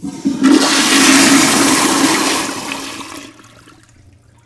Heavy Toilet Flush Sound
household
Heavy Toilet Flush